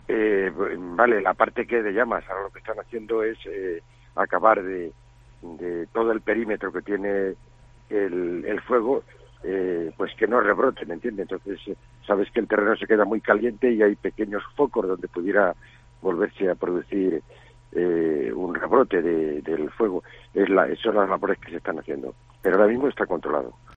José Manuel Jiménez Gil, alcalde de Navarredonda de Gredos